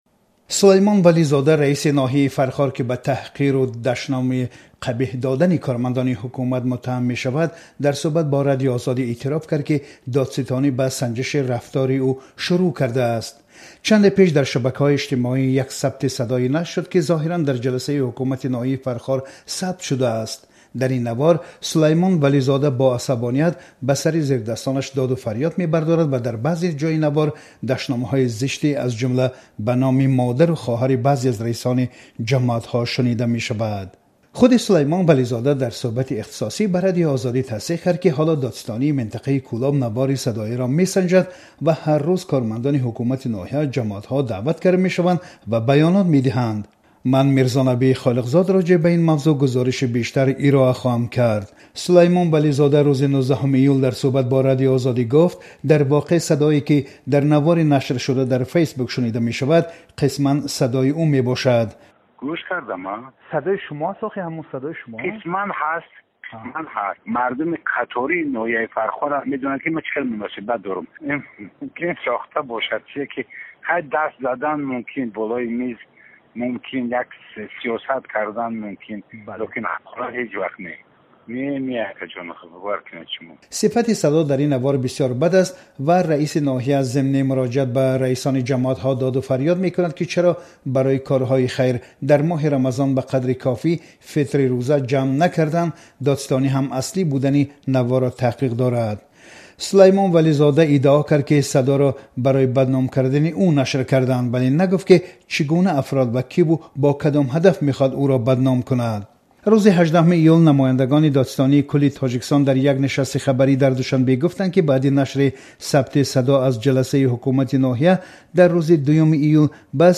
Сулаймон Вализода, раиси ноҳияи Фархор, ки ба таҳқири кормандони ҳукумат муттаҳам мешавад, дар сӯҳбат бо Радиои Озодӣ эътироф кард, ки додситонӣ ба санҷиши рафтори ӯ шурӯъ кардааст.